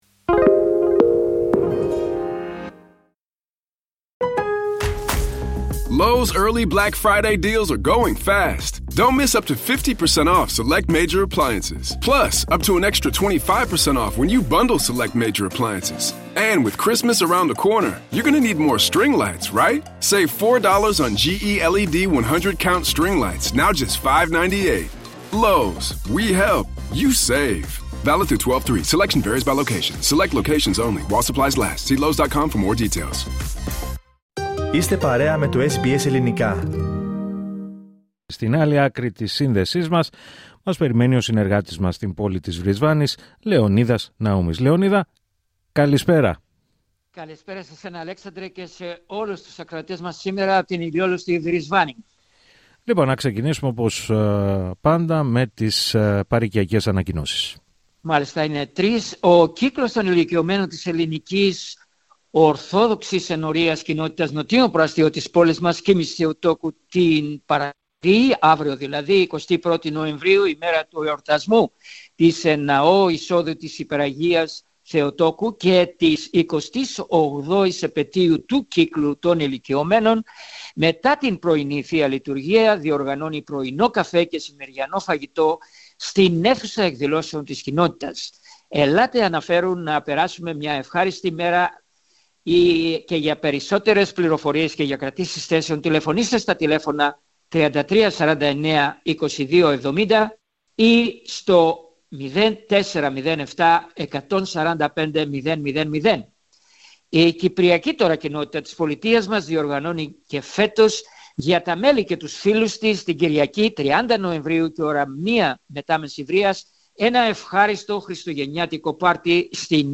Ανταπόκριση-Βρισβάνη: Οσμές αποβλήτων επηρεάζουν την υγεία χιλιάδων κατοίκων στο Ipswich